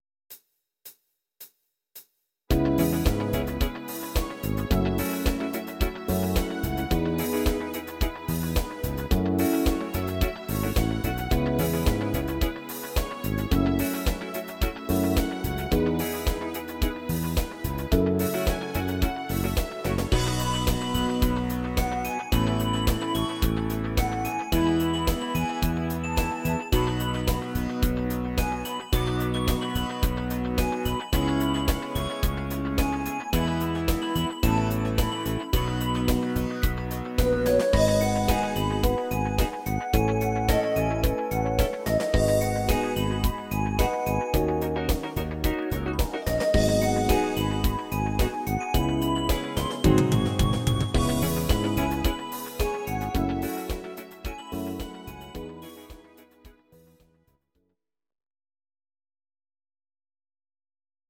These are MP3 versions of our MIDI file catalogue.
Please note: no vocals and no karaoke included.
Your-Mix: Disco (726)